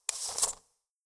water-rudder-in.wav